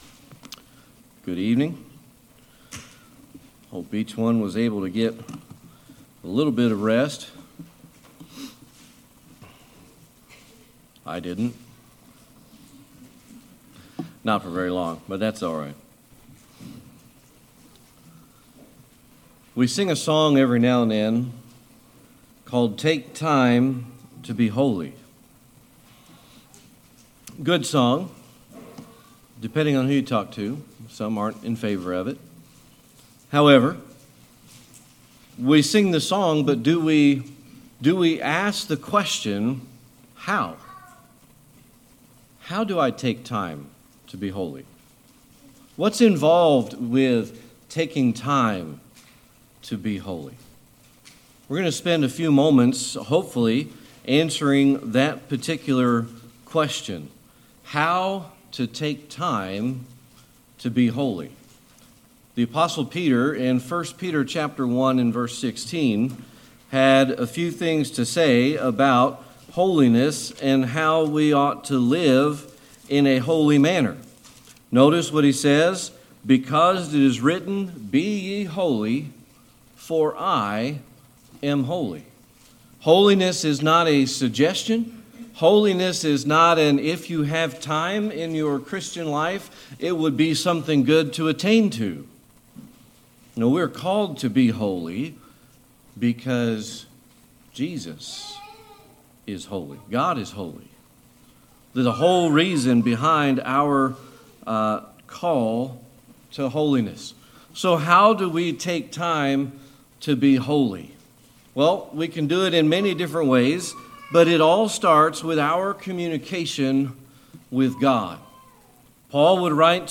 Series: Sermon Archives
1 Peter 1:16 Service Type: Sunday Evening Worship We sing a song every now and then called Take Time to Be Holy .